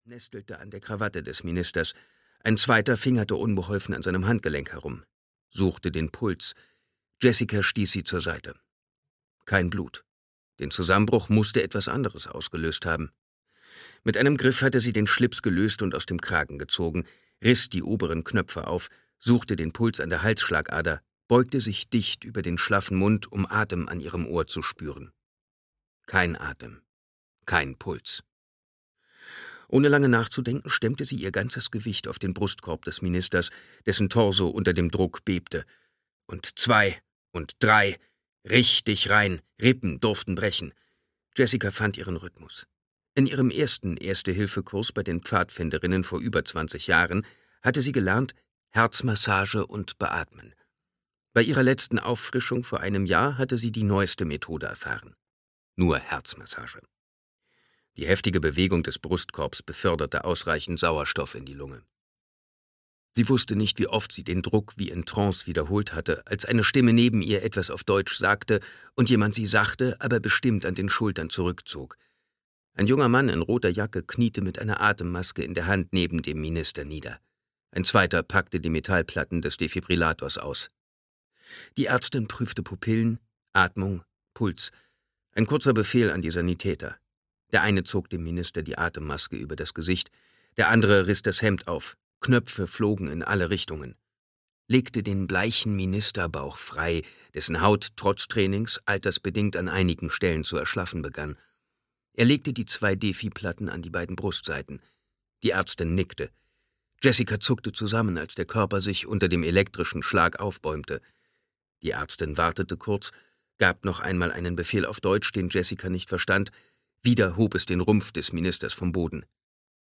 Audio knihaHELIX - Sie werden uns ersetzen (DE)
Ukázka z knihy